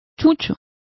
Complete with pronunciation of the translation of mongrels.